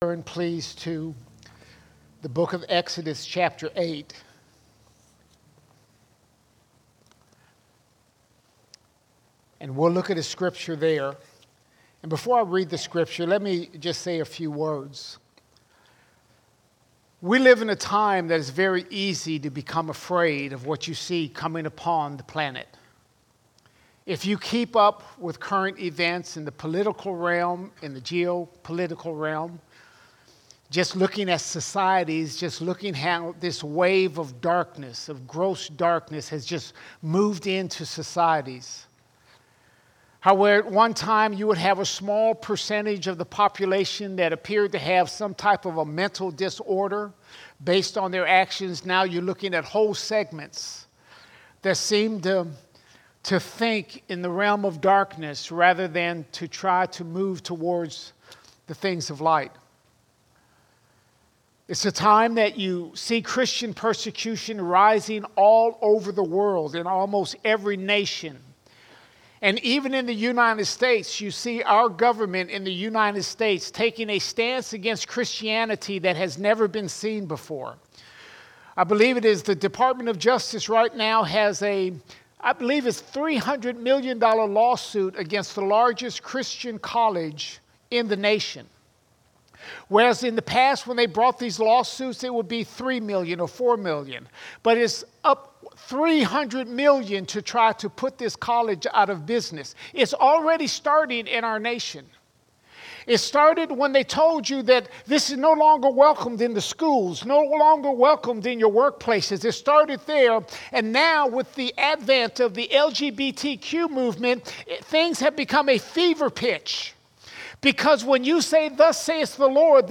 1 July 2024 Series: Sunday Sermons Topic: the world All Sermons God's Division God’s Division God has placed a division between His people and the world.